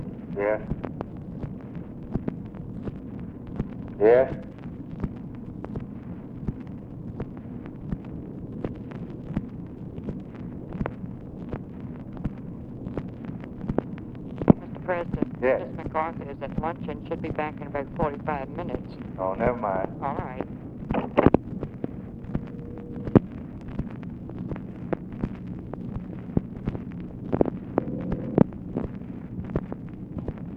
Conversation with TELEPHONE OPERATOR, February 25, 1964
Secret White House Tapes